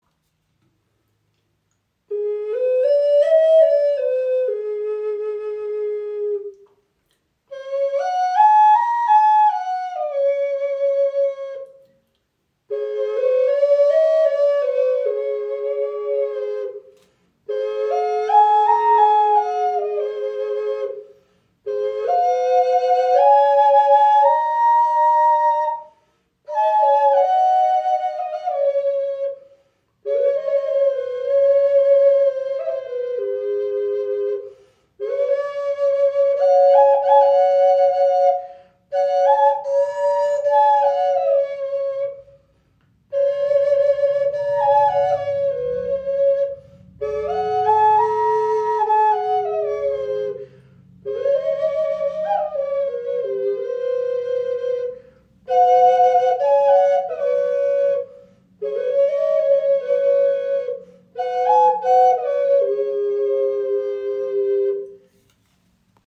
Harmonie Doppel Okarina aus Kuhhörnern | A4 & E5 in 432 Hz | ca. 24 cm
Dieses handgefertigte Einzelstück aus natürlichen Kuhhörnern vereint zwei Stimmen in einem Instrument. Gestimmt auf 432 Hz in A4 und E5 entfaltet es einen warmen, klaren Klang, der Herz und Seele berührt.
Trotz ihrer handlichen Grösse erzeugt sie einen angenehm tiefen und warmen Klang – fast ebenbürtig zur nordamerikanischen Gebetsflöte.